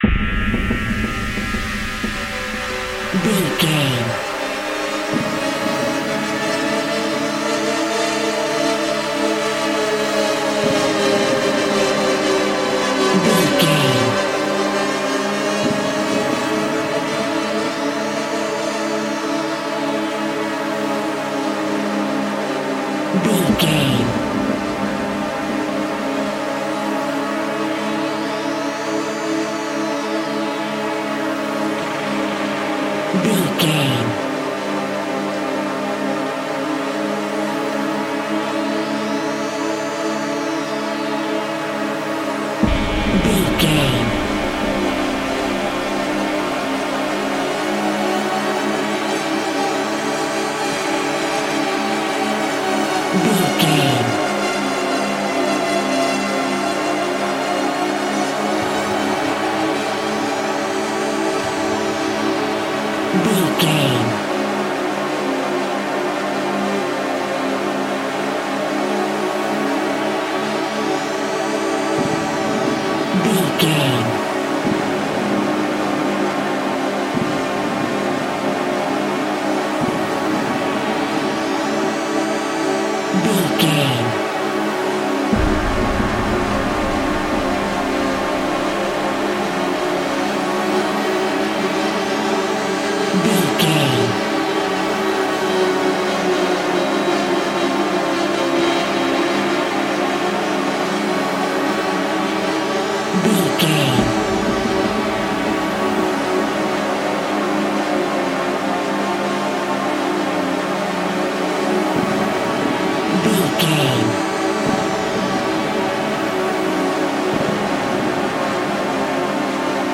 Atonal
Slow
scary
ominous
suspense
eerie
strings
synthesiser
instrumentals
horror music